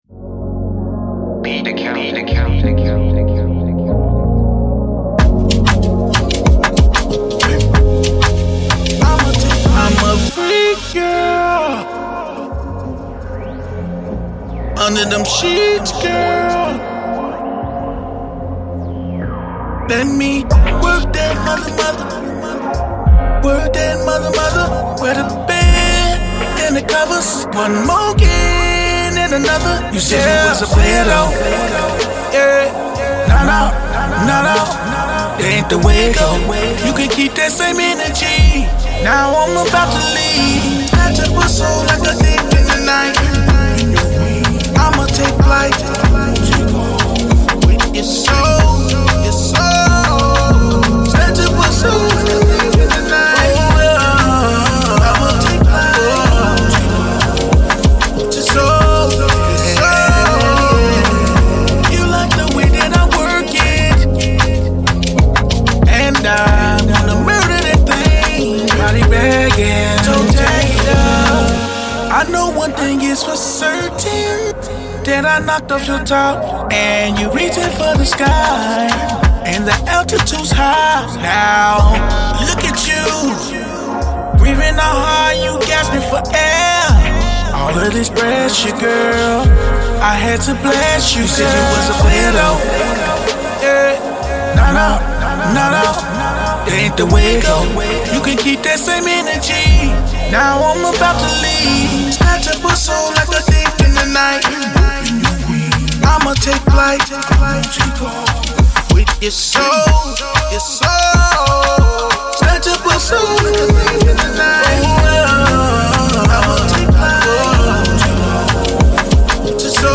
RnB